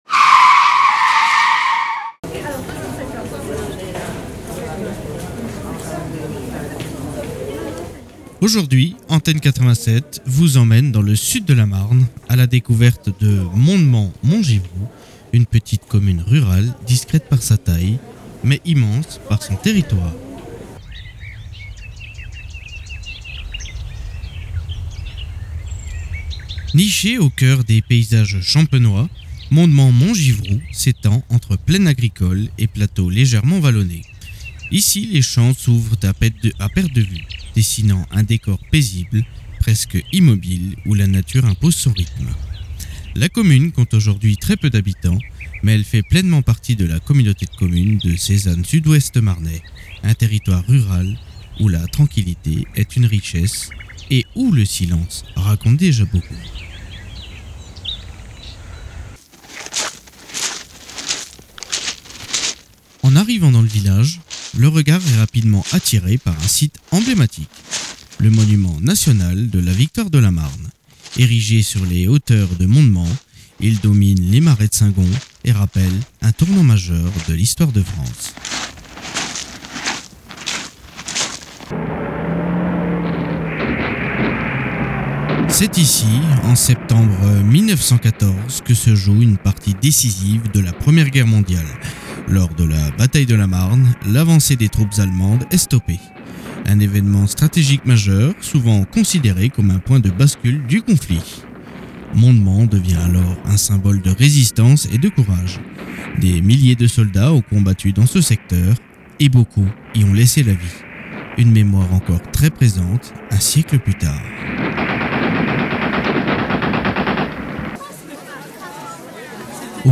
Les Trésors Cachés, c’est une promenade sonore au cœur des Plaines de Champagne, à la rencontre d’un passé vivant et d’un patrimoine qui mérite d’être transmis.